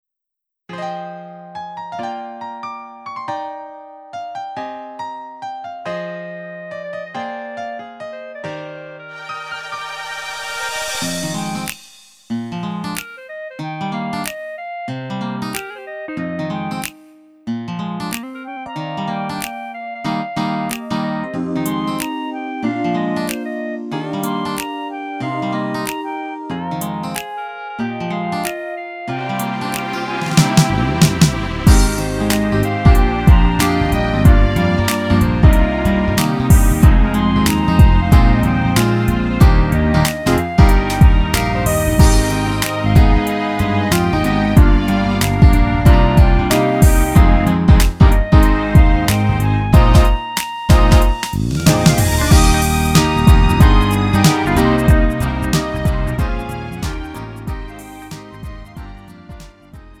음정 -1키 3:23
장르 가요 구분 Lite MR